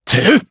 Lusikiel-Vox_Attack3_jp.wav